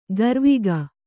♪ garviga